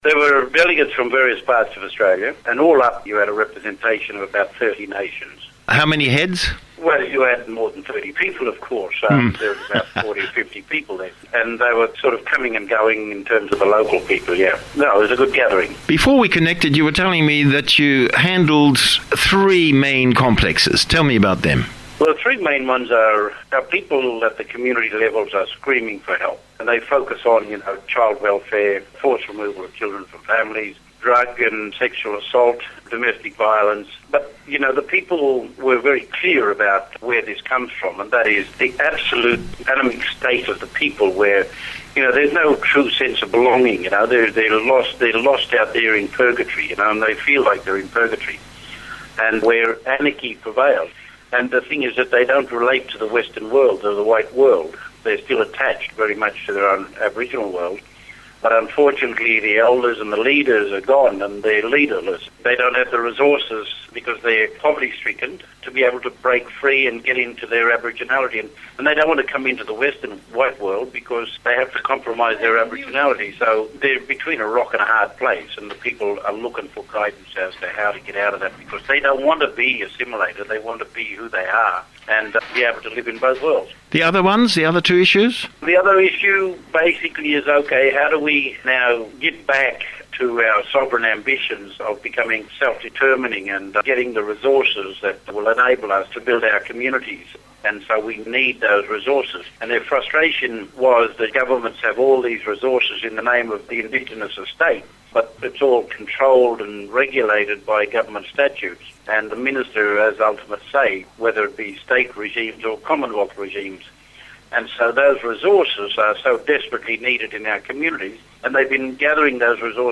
Interview following Moree Gathering